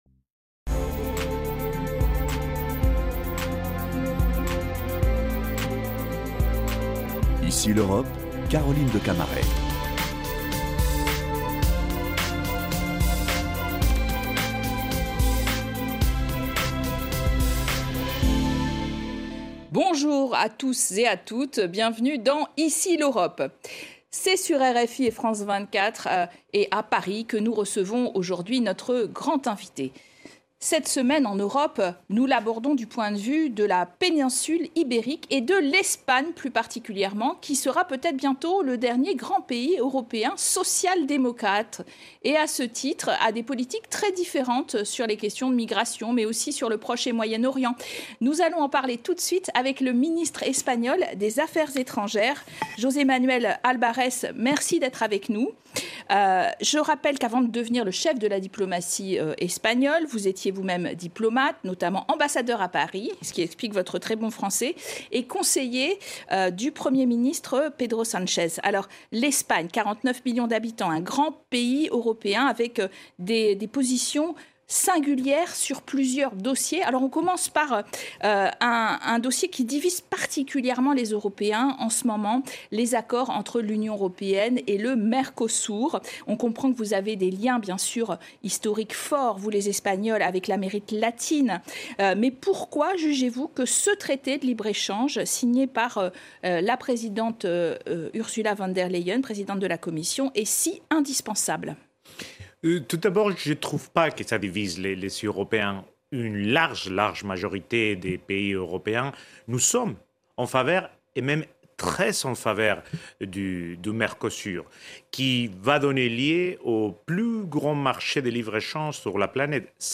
Cette semaine, nous recevons José Manuel Albares, ministre espagnol des Affaires étrangères au sein du gouvernement socialiste de Pedro Sánchez. Accord du Mercosur, immigration et conflits dans diverses régions du monde, il commente les défis auxquels l’Union européenne doit faire face.